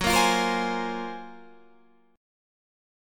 GbM#11 chord